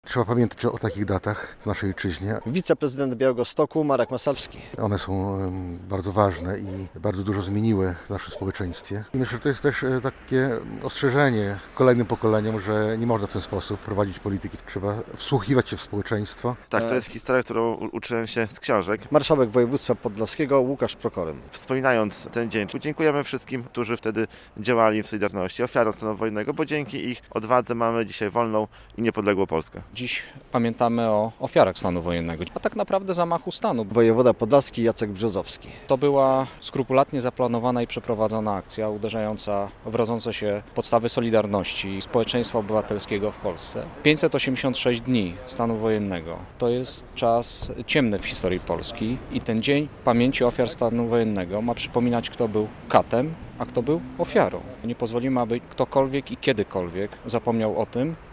Obchody 43. rocznicy wprowadzenia stanu wojennego w Białymstoku - relacja